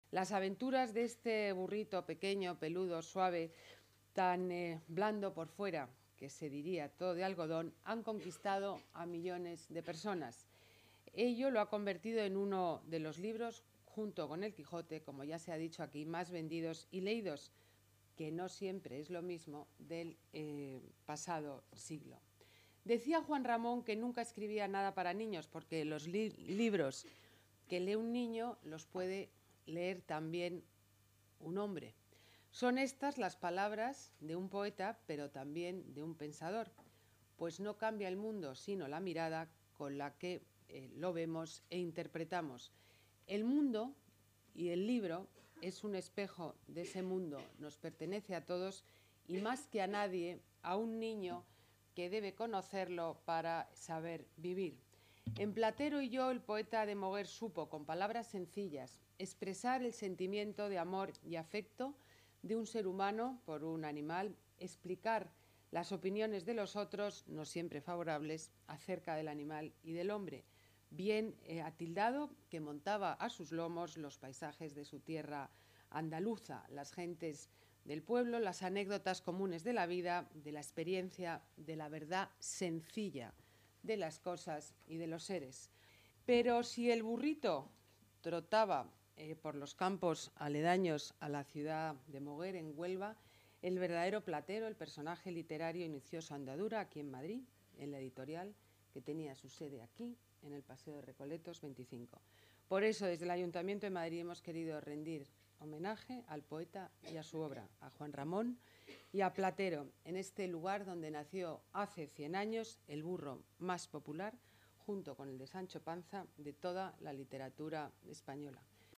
Nueva ventana:Declaraciones de la alcaldesa, Ana Botella: Aniversario Platero y yo